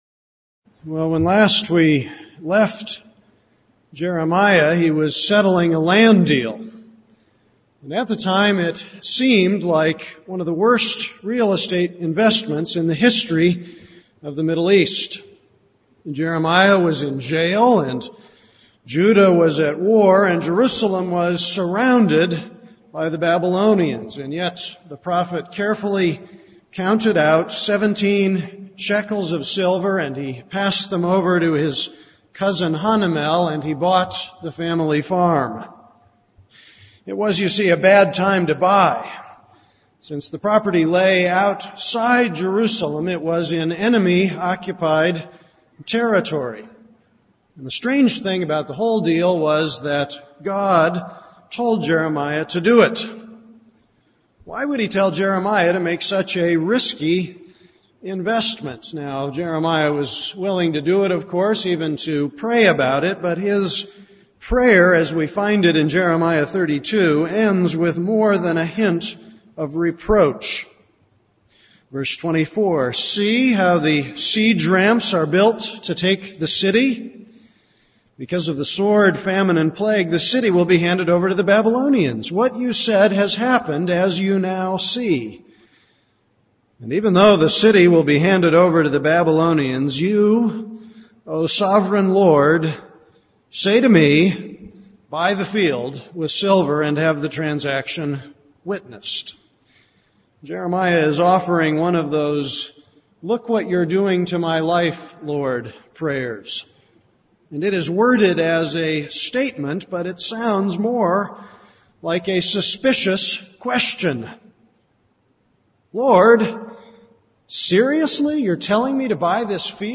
This is a sermon on Jeremiah 32:26-44.